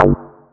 Lis Bass.wav